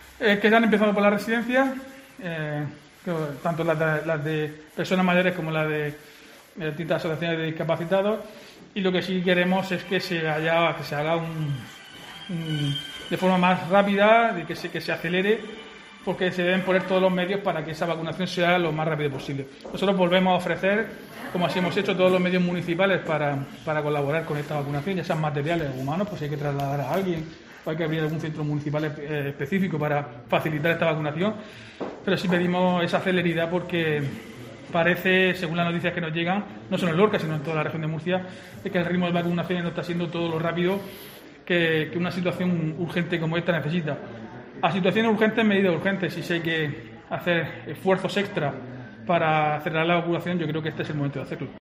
Diego José Mateos, alcalde de Lorca sobrre vacunas